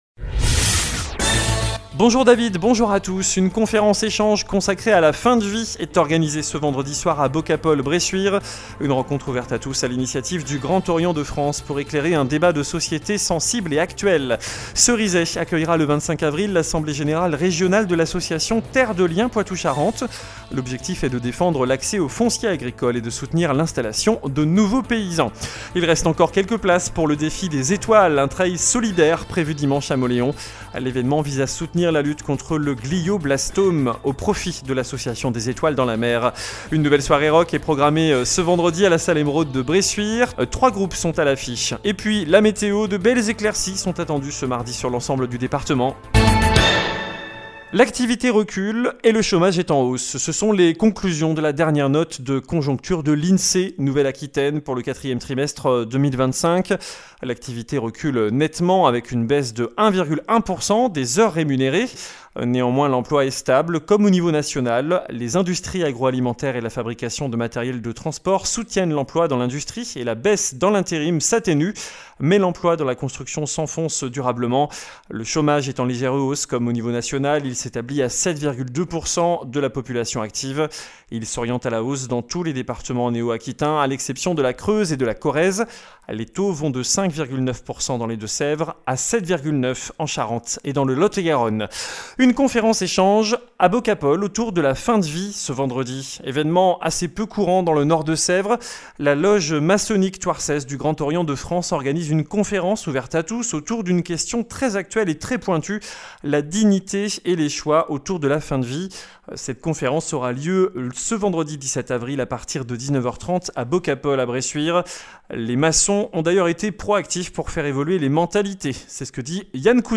Journal du mardi 14 avril (midi)